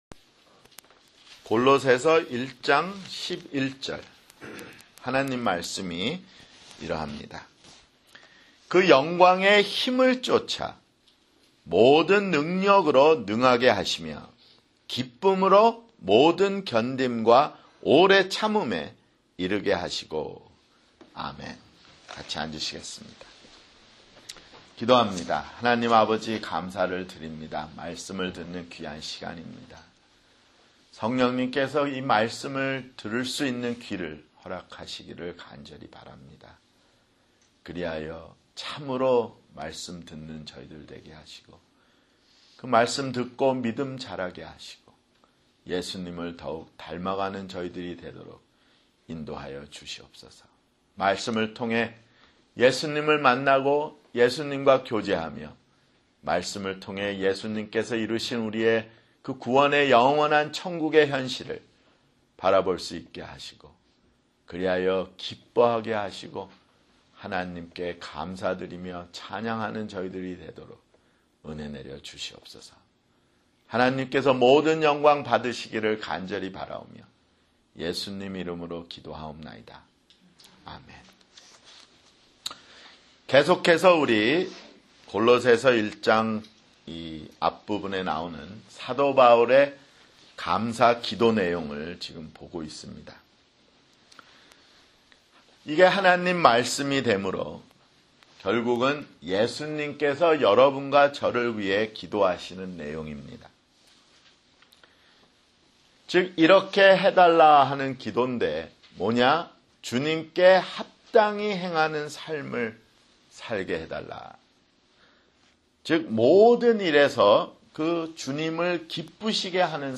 [주일설교] 골로새서 (21)